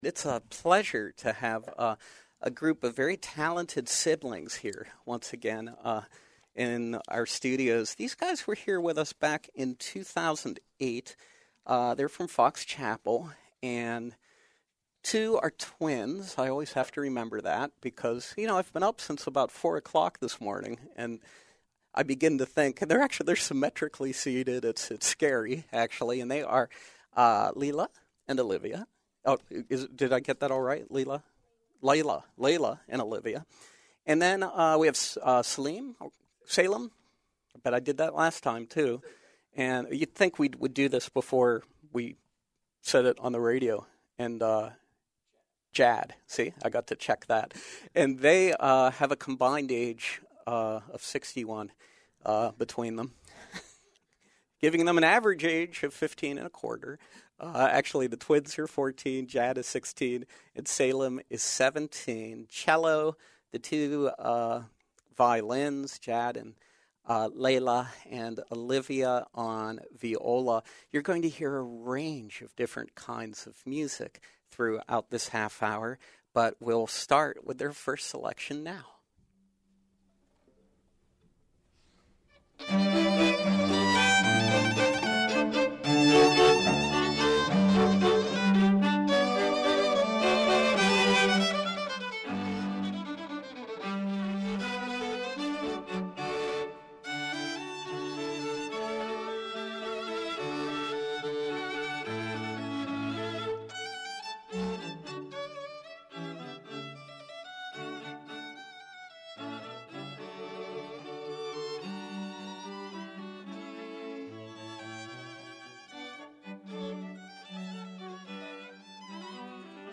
As part of our ongoing series with the Pittsburgh Youth Symphony Orchestra, this week we welcome the talented Hilal Quartet, a group of siblings who have been performing together for over seven years.